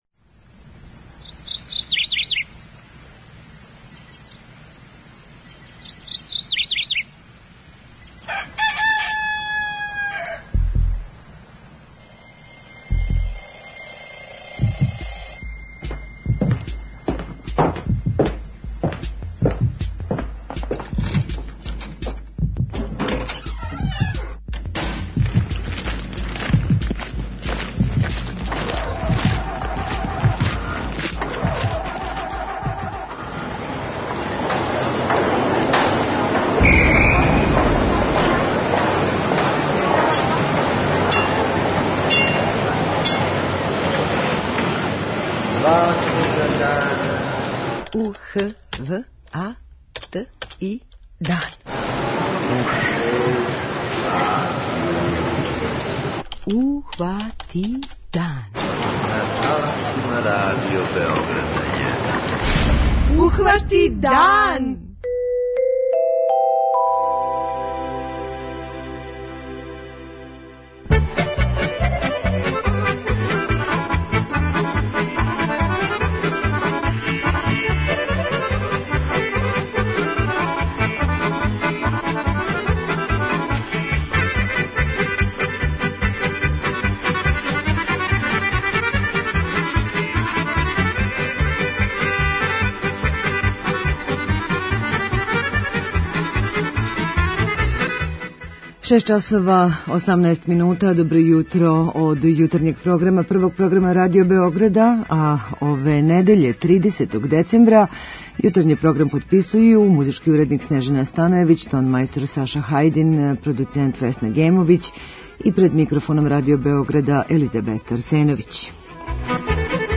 Ове недеље, претпоследњег дана 2018. године, у Јутарњем програму - 105 минута добре народне музике, најважнијих сервисних информација у сусрет дочеку нове 2019, приче о две изложбе у Београду, као и о хранитељству и преради воћа у Шумадији.